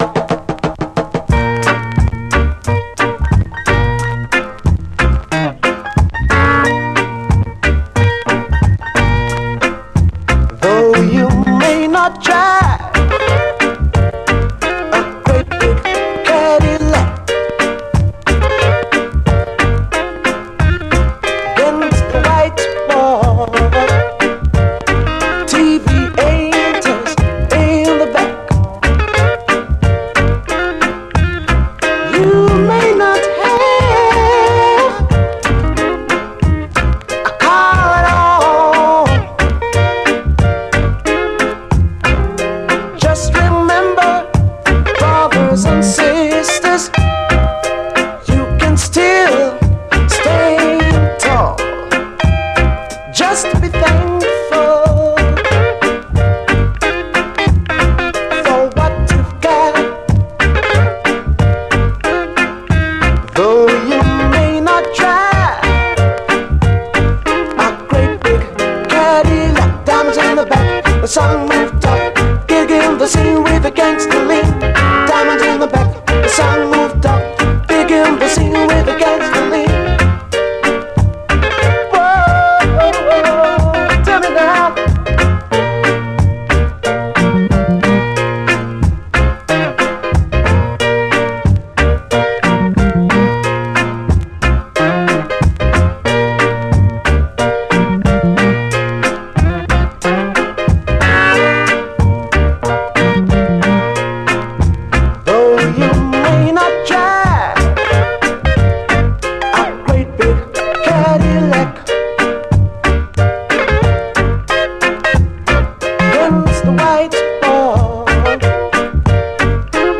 REGGAE, 7INCH
エンジン音入りのダブ・ヴァージョン